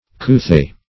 Coothay \Coo*thay"\ (k[=oo]*th[=a]"), n. A striped satin made in India.